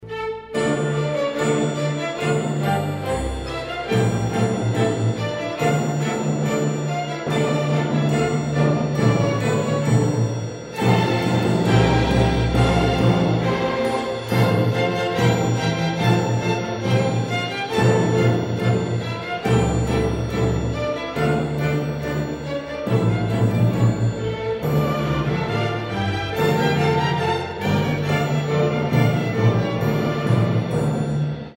Symphony for the strings no.11 in F, Scherzo (Commodo Swiss Song), using an Emmental Wedding March.